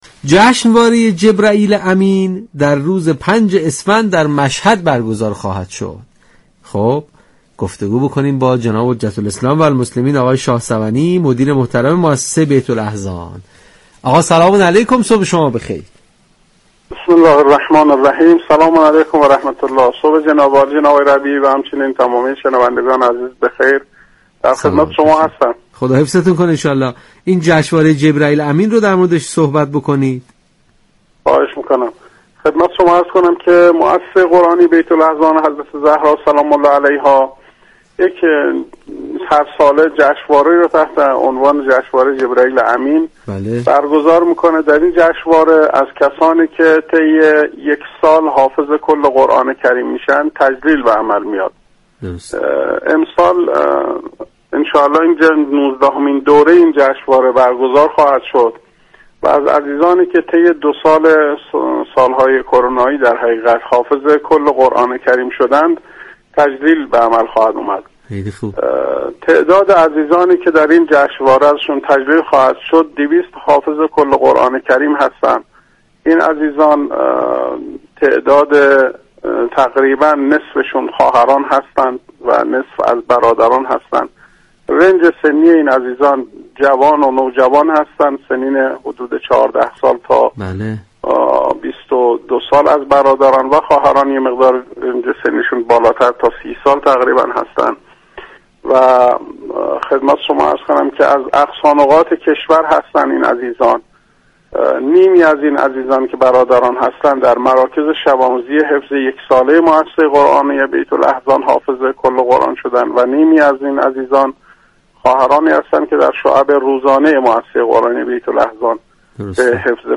در گفتگو با برنامه تسنیم رادیو قرآن، از برگزاری نوزدهمین جشنواره ملی جبرئیل امین این مؤسسه در اسفندماه سال جاری با هدف تجلیل از حافظان كل قرآن كریم سالهای 99 و 1400 این مؤسسه در مشهد مقدس خبر داد.